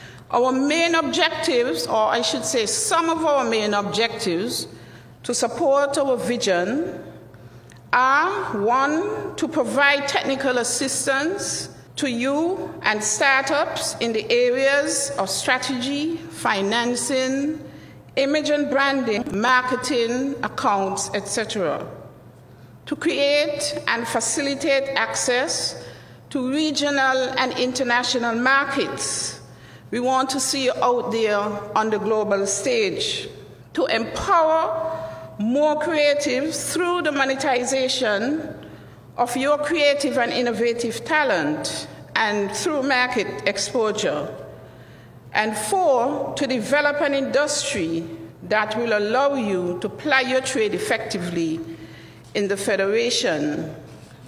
On Friday October 29th the Ministry of Entertainment, Entrepreneurship and Talent Development (MoENT) held a Creative Forum at the Marriott Resort in St. Kitts.
During the forum, Permanent Secretary in the Ministry, Mrs. Cheryleann Pemberton highlighted some of the aims of the Ministry to attendees of the forum.